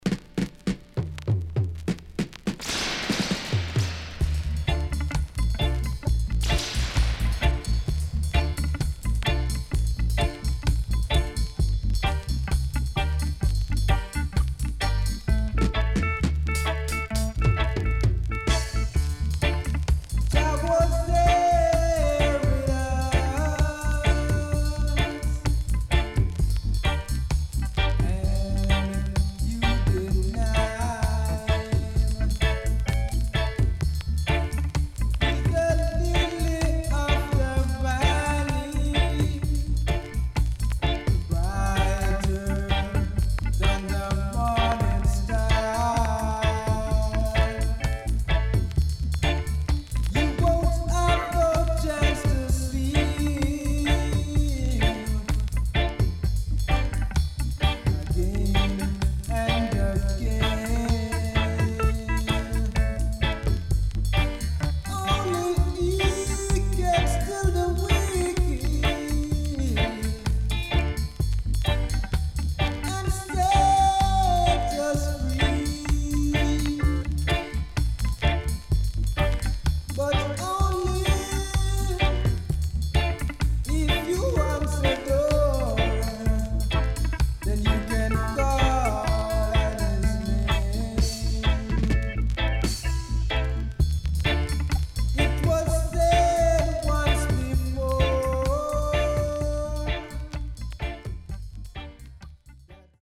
SIDE A:全体的にチリノイズがあり、少しプチノイズ入ります。